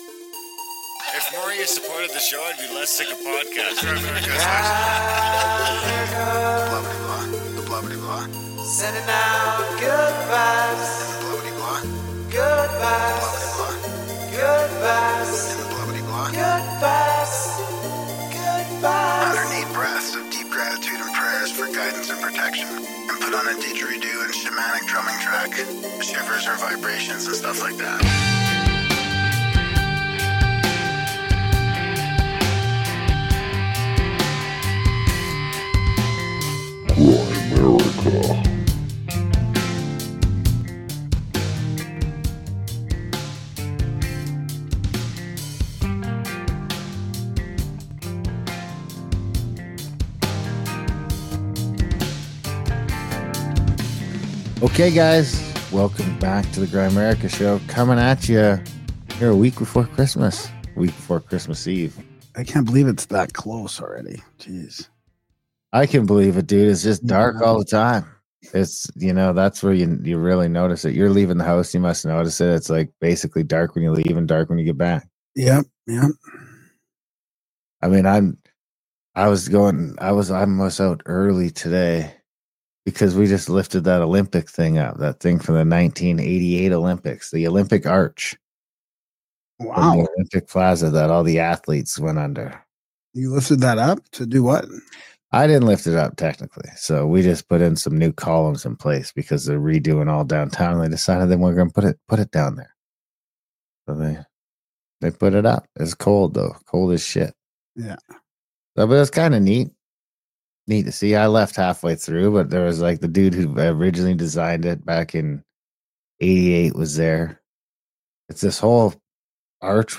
Interview starts at 31:05